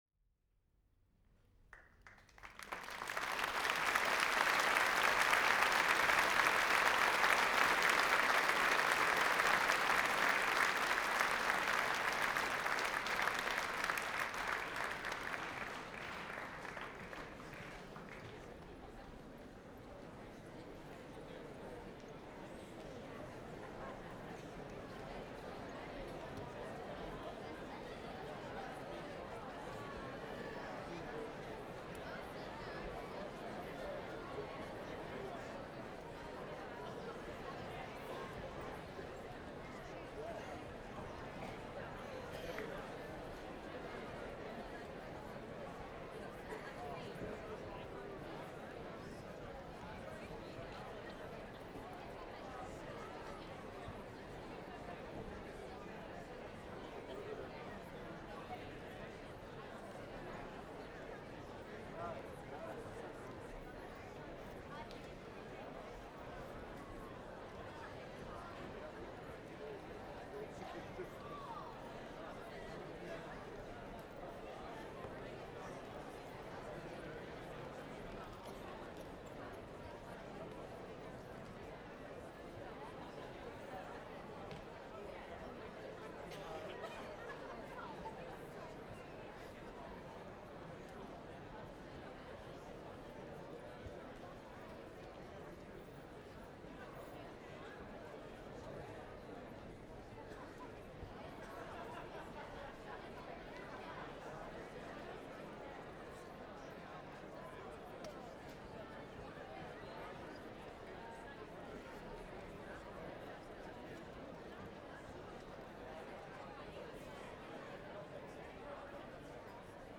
Applause and Murmur 1
These are a series of recordings made in The Royal Shakespeare Theatre in Stratford on Avon, during performances of Romeo & Juliet. The microphone was mounted at the front of the first balcony, about 50 cm away from the balcony edge. There are two recordings made at the interval of the show, both including some audience noise, one recording made at the end of the show, and one recording of more sparse audience chatter in the auditorium. Individual voices are mainly American as Stratford is a hugely popular tourist destination.
Ambisonic
Ambisonic order: F (4 ch) 1st order 3D
Microphone name: Soundfield ST250
Array type: Tetrahedron